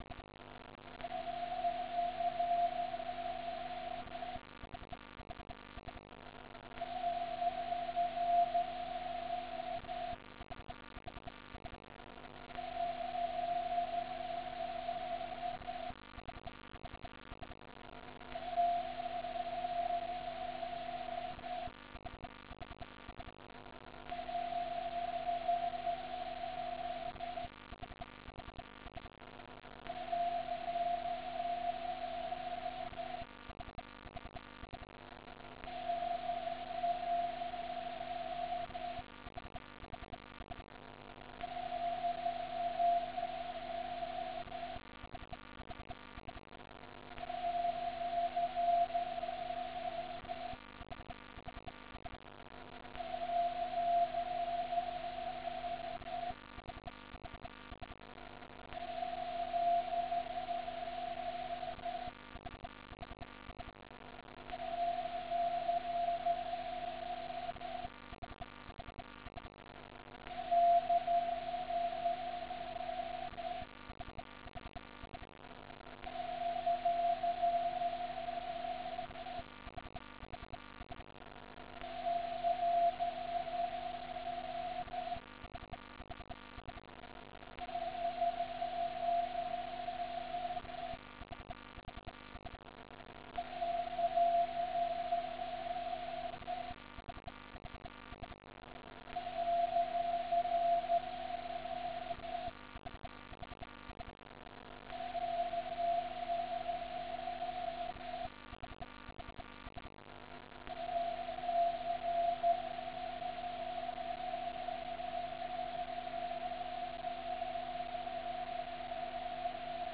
Moonbounce Echo Tests:
These were around 10-15dB over the noise and so I tried to record some signals to verify the performance of the FT847's receiver.
The conditions seemed to be very stable at that time, there was nearly no QSB or fading.
First test: Just echos while CW-Bandwidth filtered to 25Hz. At the bottom you see a short time with just the noise of the background. There is a spurious of the TX signal at around 1170Hz.